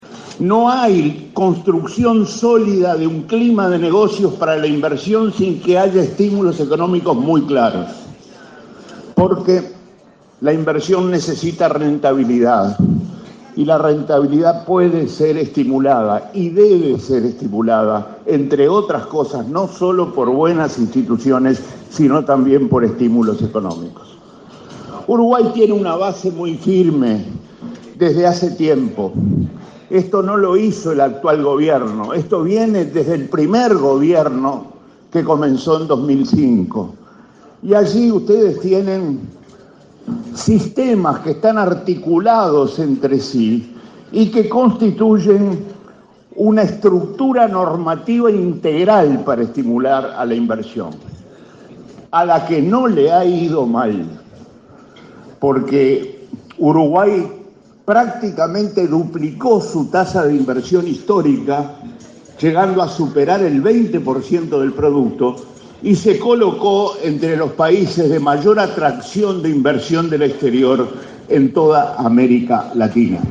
Uruguay duplicó su tasa de inversión histórica, que llegó a superar el 20 % del PBI, y se convirtió en el país de más atracción de inversión del exterior en toda América Latina, afirmó el ministro de Economía, Danilo Astori, en su disertación en ADM este miércoles 29.